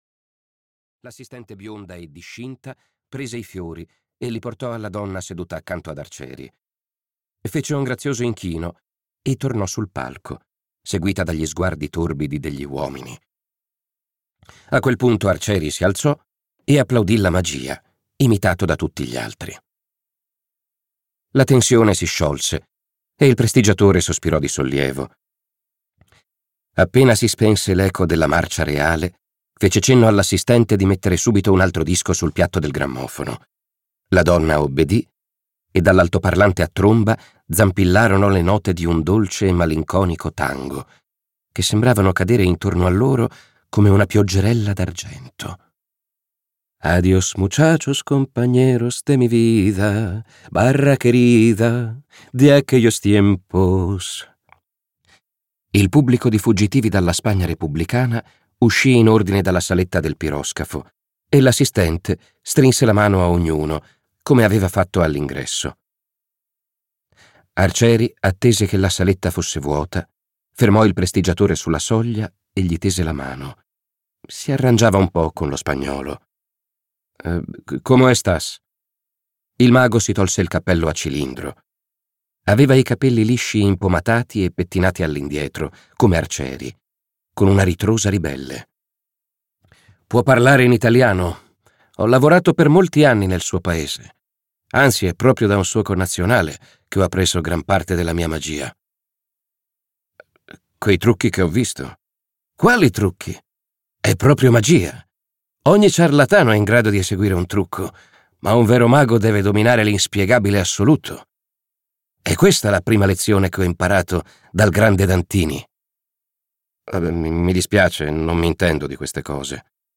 "La nave dei vinti" di Leonardo Gori - Audiolibro digitale - AUDIOLIBRI LIQUIDI - Il Libraio